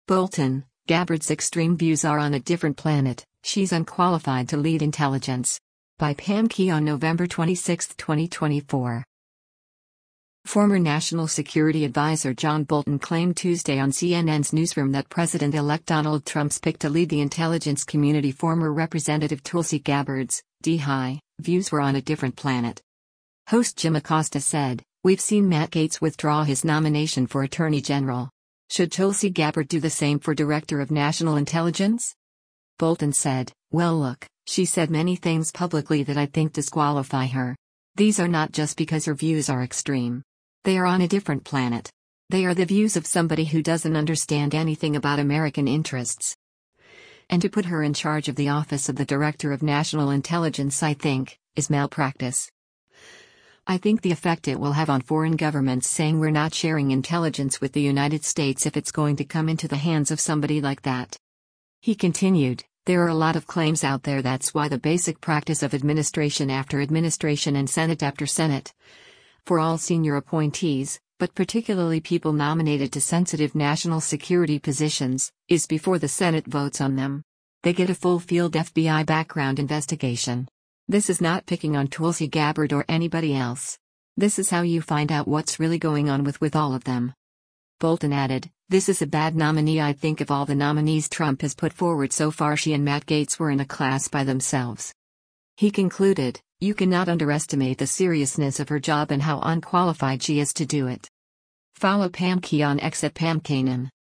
Former National Security Advisor John Bolton claimed Tuesday on CNN’s “Newsroom” that President-elect Donald Trump’s pick to lead the intelligence community former Rep. Tulsi Gabbard’s (D-HI) views were “on a different planet.”
Host Jim Acosta said, “We’ve seen Matt Gaetz withdraw his nomination for attorney general. Should Tulsi Gabbard do the same for Director of National Intelligence?”